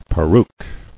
[pe·ruke]